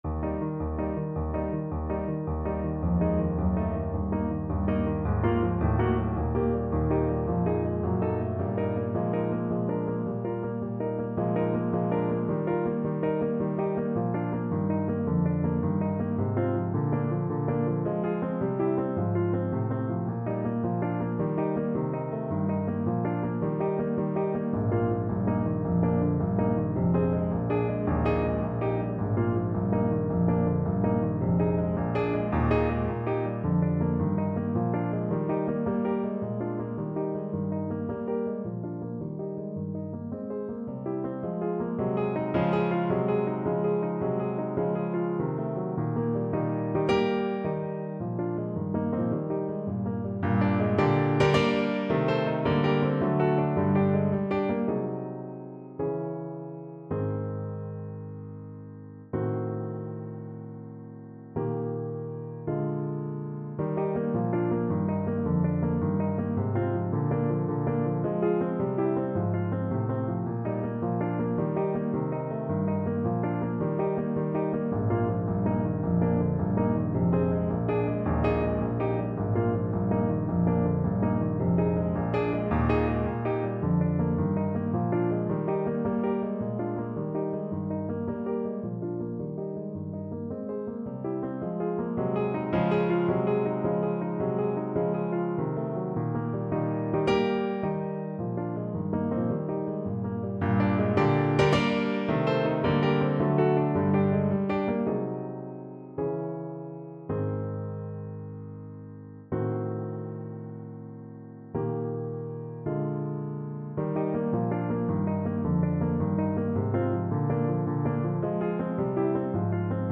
Trumpet
Eb major (Sounding Pitch) F major (Trumpet in Bb) (View more Eb major Music for Trumpet )
= 140 Geschwind
2/4 (View more 2/4 Music)
Classical (View more Classical Trumpet Music)